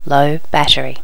low battery.wav